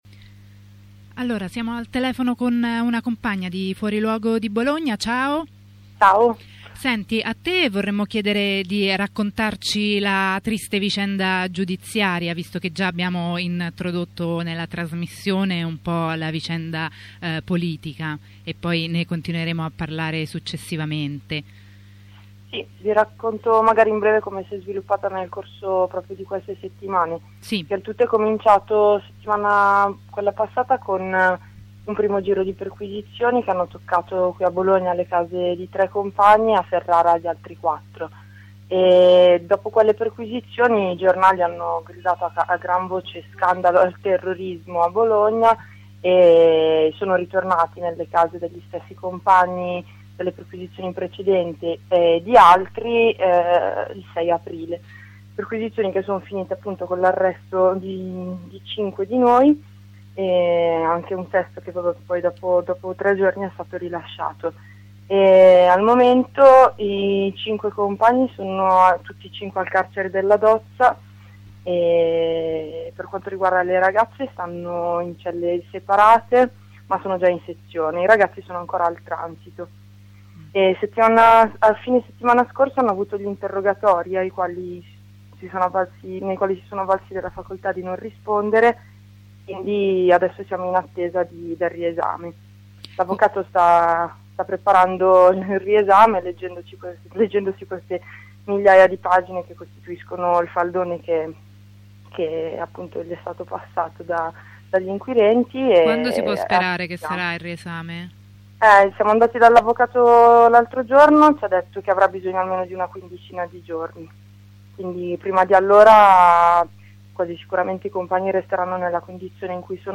Ascolta una corrispondenza a una settimana dagli arrersti di 5 compagne/i anarchici di Fuoriluogo, impegnati nelle lotte contro i Cie e nello smascherare gli interessi di grandie aziende quali l'Eni.